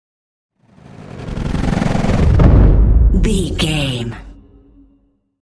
Chopper whoosh to hit large trailer
Sound Effects
Atonal
dark
futuristic
intense
tension
woosh to hit